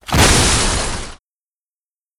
damage-heavy.wav